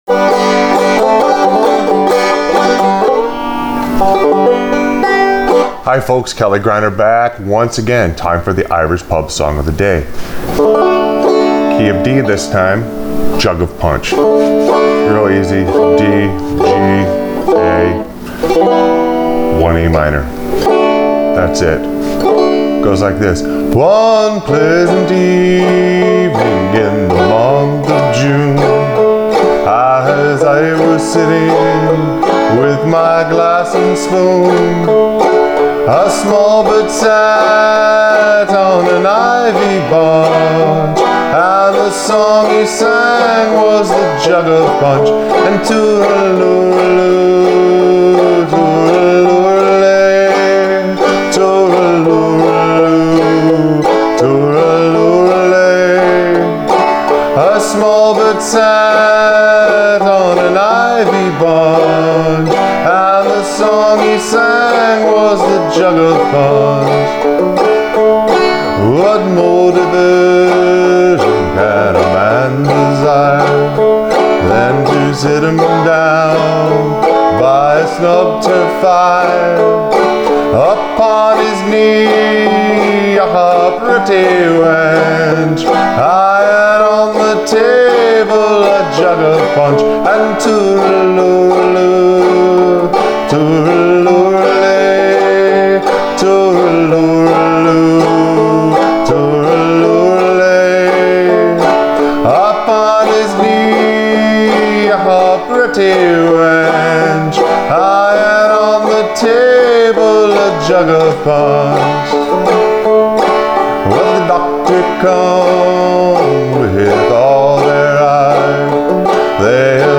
Irish Pub Song Of The Day – Jug Of Punch on Frailing Banjo
This is a great song and I love to play it in the key of D on 5 string banjo in Open G tuning.